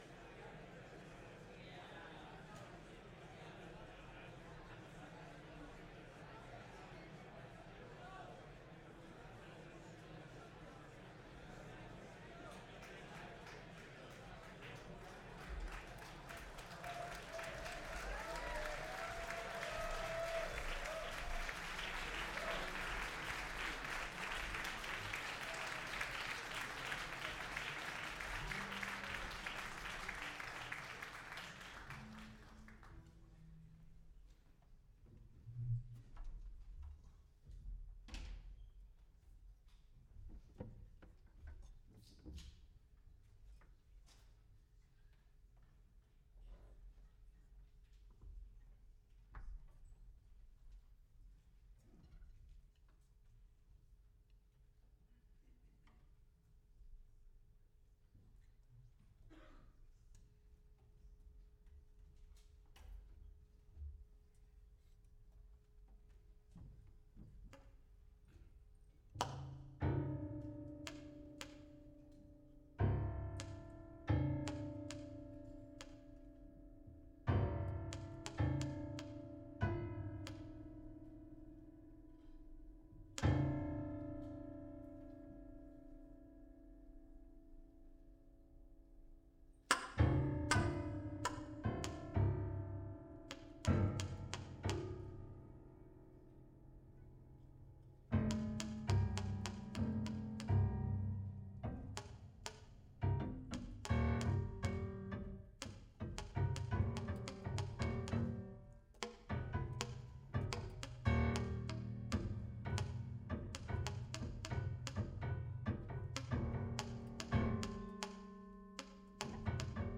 UK quartet reimagining the work of the late musician
piano
drums
bass
alto saxophone
Genres: Jazz